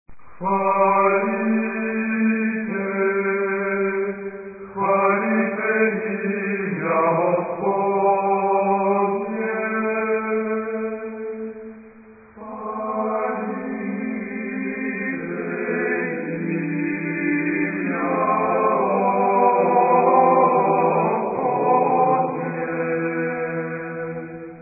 SATBB (5 voices mixed) ; Full score.
Tonality: A minor